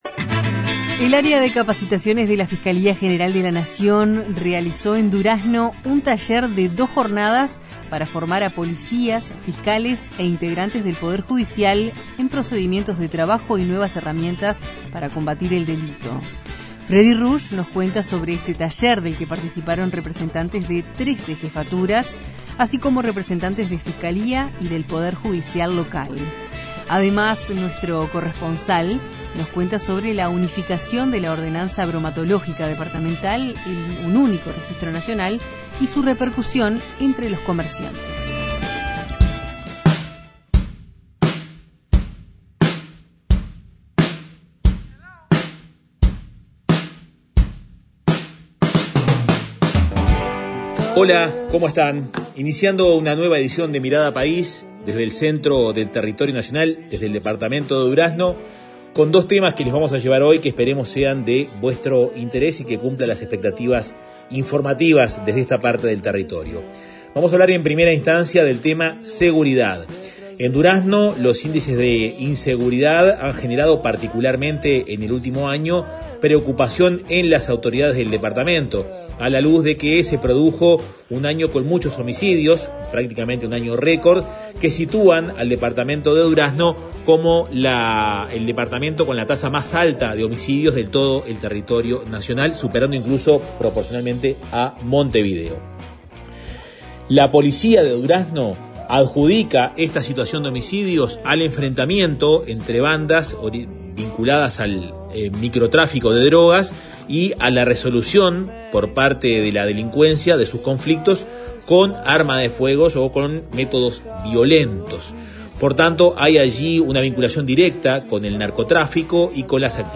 Los informes de nuestros corresponsales en Colonia (Oeste), Durazno y Soriano.